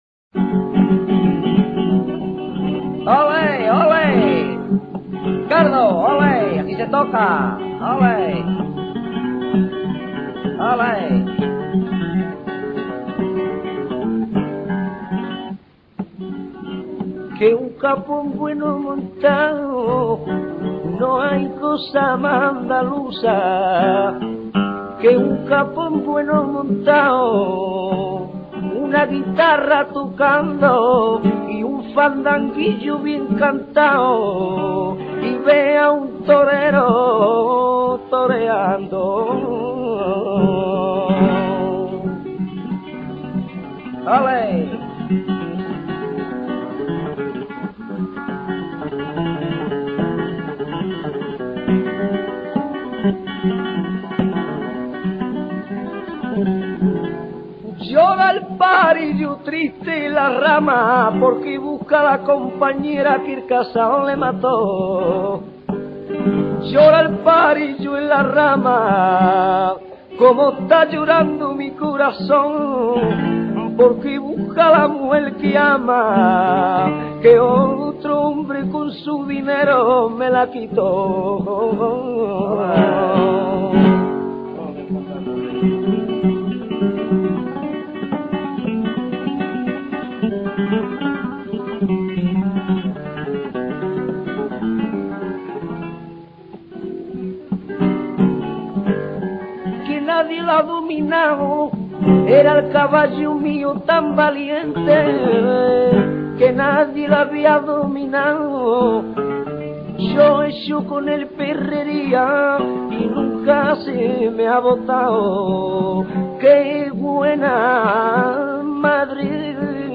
FANDANGUILLO, m. Fandango, especialmente el bailable de cada comarca.
fandanguillo.mp3